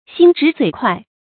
心直嘴快 xīn zhí zuǐ kuài 成语解释 见“心直口快”。
ㄒㄧㄣ ㄓㄧˊ ㄗㄨㄟˇ ㄎㄨㄞˋ